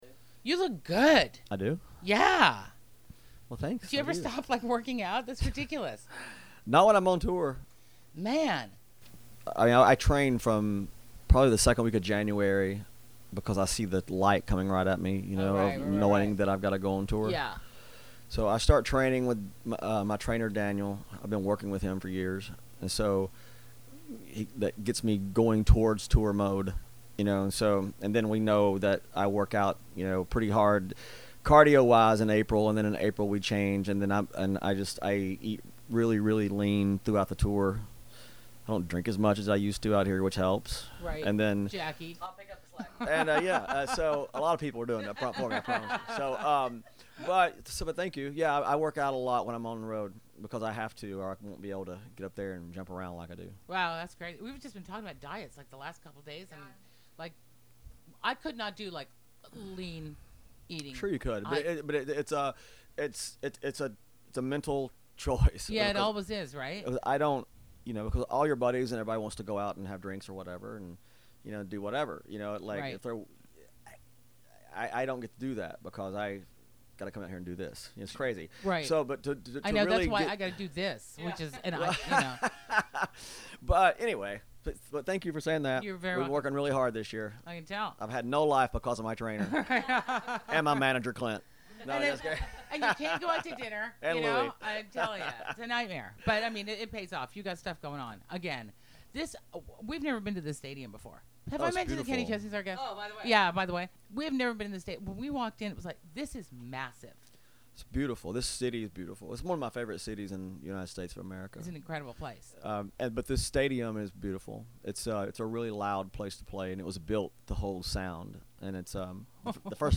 Backstage Broadcast At Trip Around The Sun Tour: Kenny Chesney
The Big Time With Whitney Allen broadcasted live at the CenturyLink Field in Seattle, WA for the Trip Around The Sun tour with Kenny Chesney, Thomas Rhett, Old Dominion, and Brandon Lay!